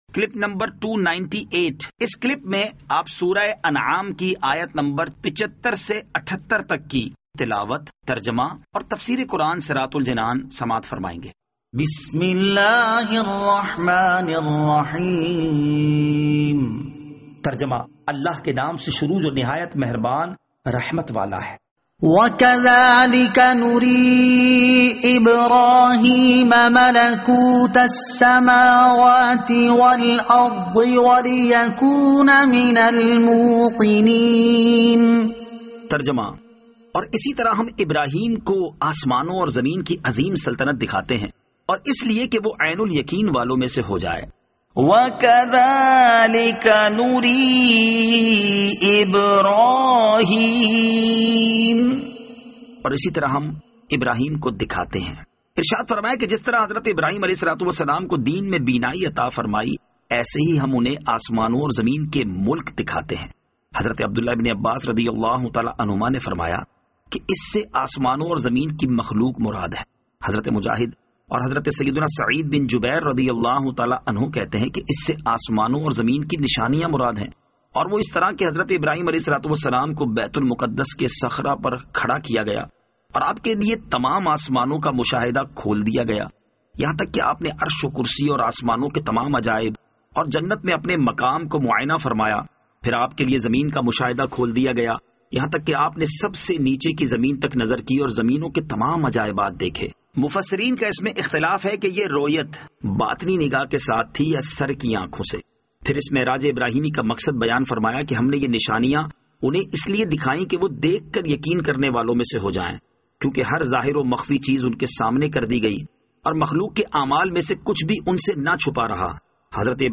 Surah Al-Anaam Ayat 75 To 78 Tilawat , Tarjama , Tafseer